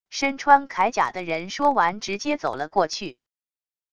身穿铠甲的人说完直接走了过去wav音频